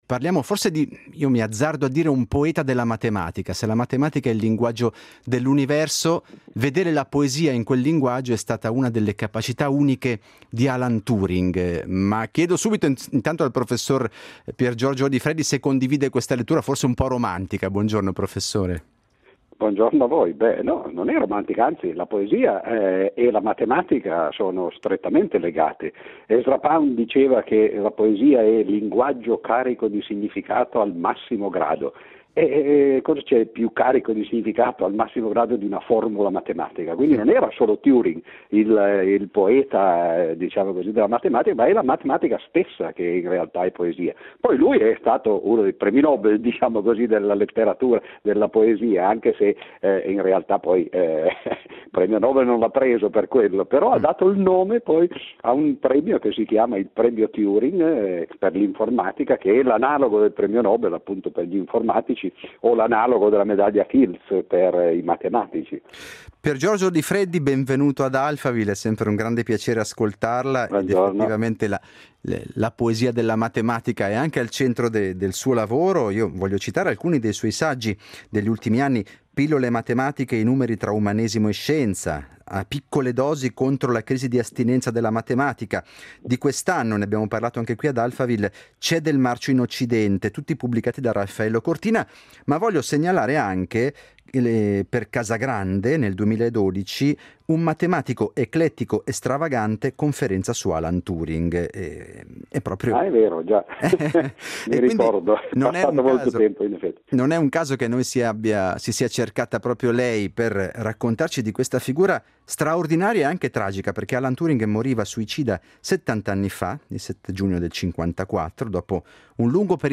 Ci aiuta a ricordarne l’importanza cruciale il matematico, scrittore e divulgatore Piergiorgio Odifreddi.